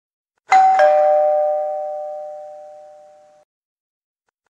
Haustür klingelton
Kategorie: Klingeltöne
Beschreibung: Mit diesem traditionellen Türklingel-Sound hören Sie das bekannte "Ding Dong" bei jedem Besuch – eine einfache und effektive Möglichkeit, Ihre Benachrichtigungen zu personalisieren.
haustuer-klingelton-de-www_tiengdong_com.mp3